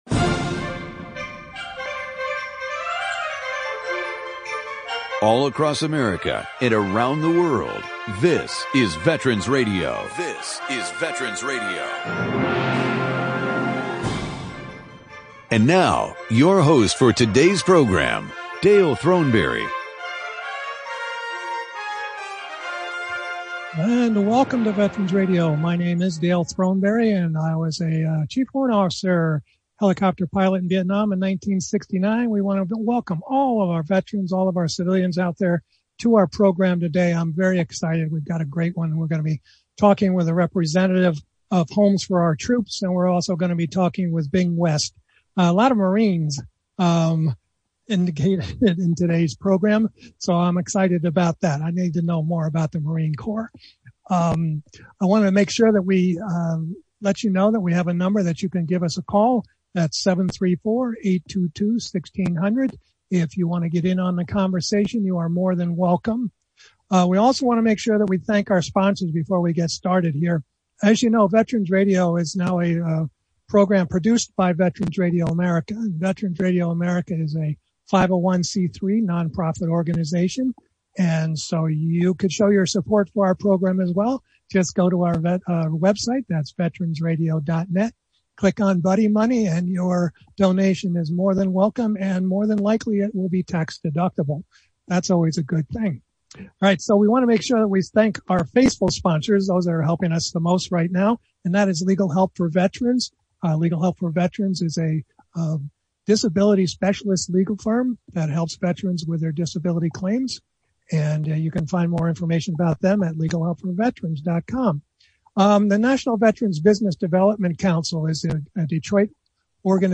Homes for Our Troops is a non-profit organization that provides free housing for disabled veterans at no cost to the veteran. In the second half of the program you will be listening to Bing West, a Vietnam marine, an advisor to the Secretary of Defense, and an author of many books.